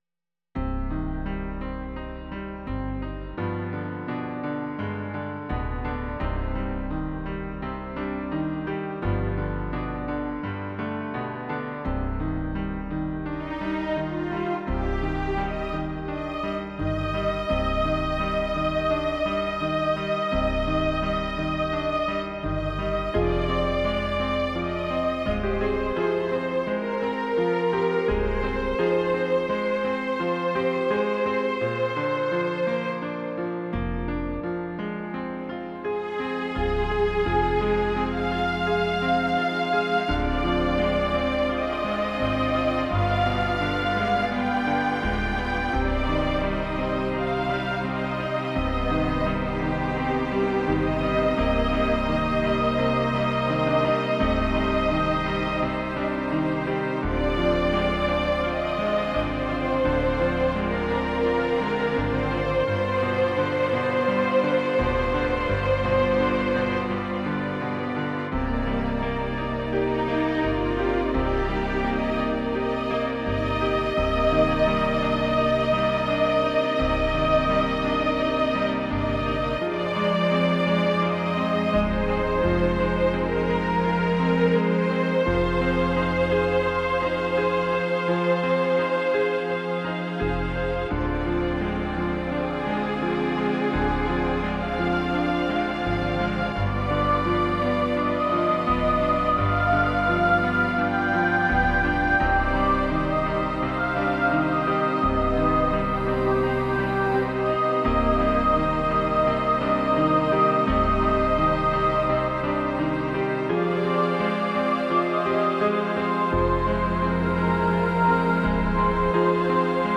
2012 トラック 4 ジャンル: Pop